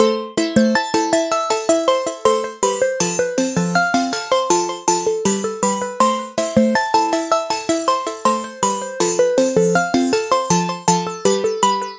我通常的Lo Fi门控合成器
描述：这是一个经典的合成器，我总是在我的样本包中使用。
Tag: 80 bpm Pop Loops Synth Loops 2.02 MB wav Key : Unknown